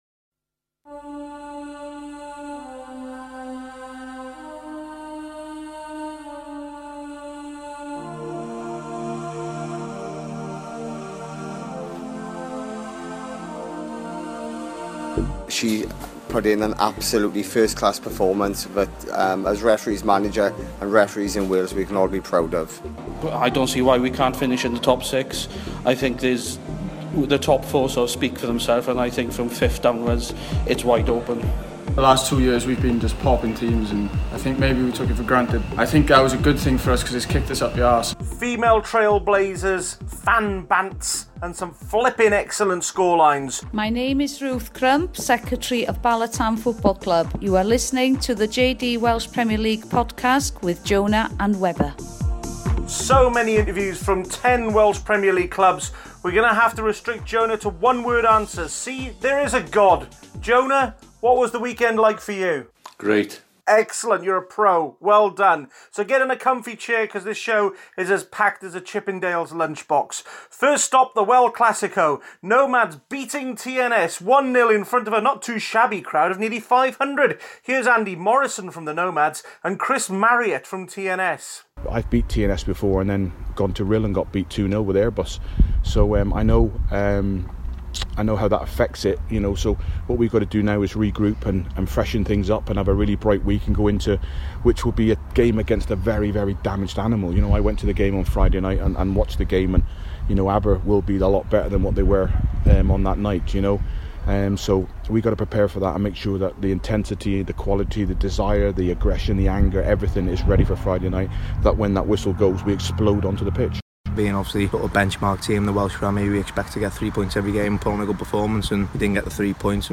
A packed podcast with managers and players from 10 clubs making an appearance.